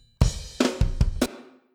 I ask because I have a few spots on a drum track where a hard hit on the...
The flam on the snare seems to have a crackle (I think more out of the left side), but it might not be clipping.